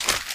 High Quality Footsteps
STEPS Newspaper, Walk 06.wav